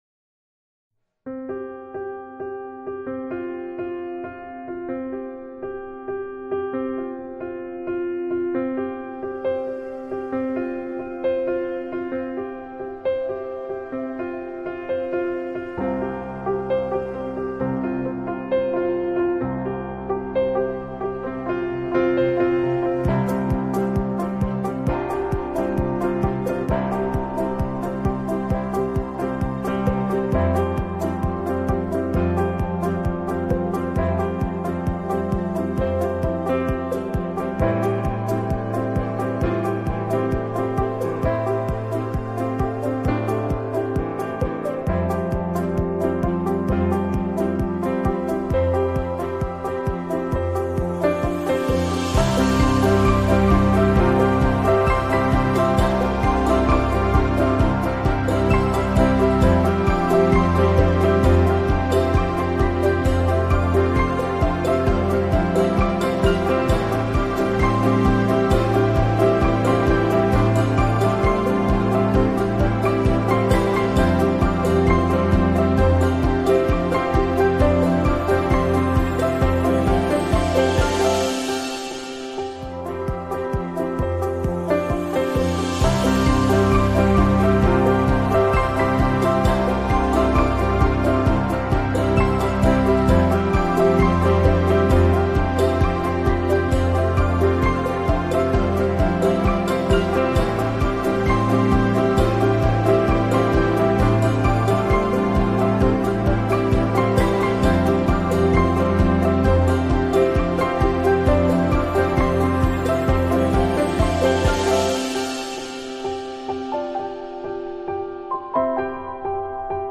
音频：多伦多观音堂十周年庆典、花絮！2022年12月11日